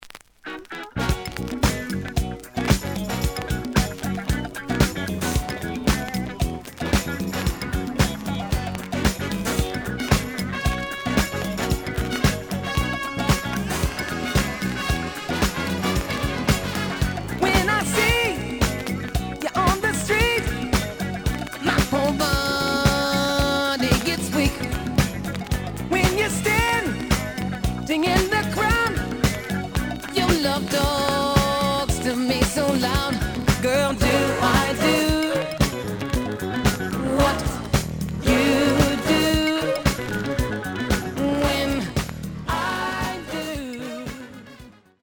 The audio sample is recorded from the actual item.
●Genre: Soul, 80's / 90's Soul
Some noise on first half of B side.